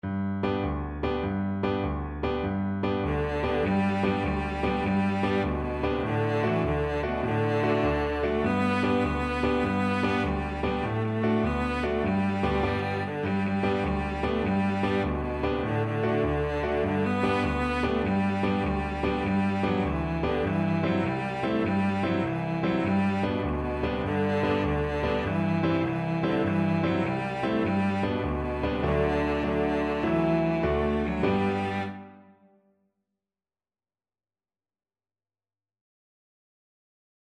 Cello version
Traditional Music of unknown author.
6/8 (View more 6/8 Music)
Allegro .=c.100 (View more music marked Allegro)